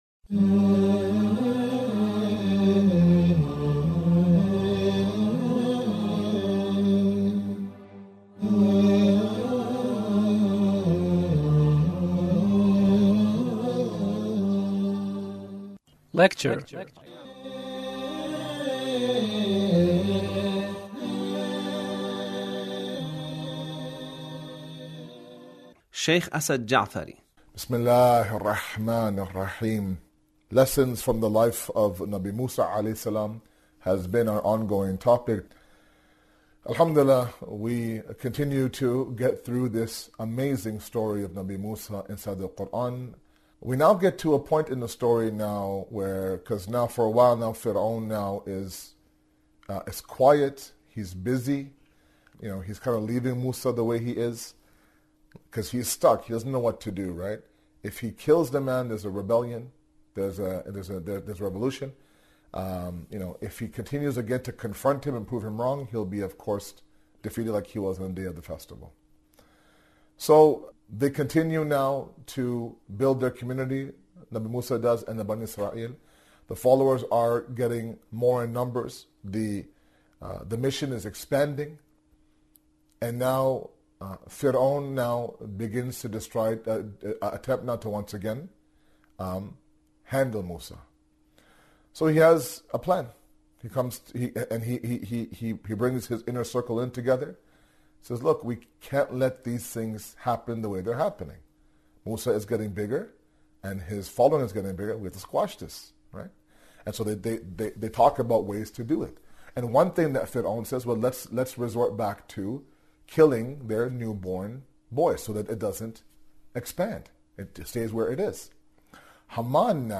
Lecture (42)